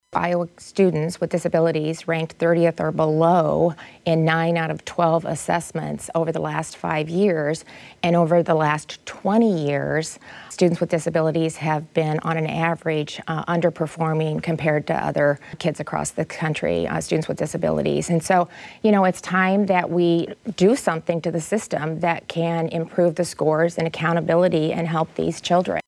REYNOLDS MADE HER COMMENTS DURING TAPING OF THE “IOWA PRESS” PROGRAM FOR FRIDAY ON IOWA P-B-S.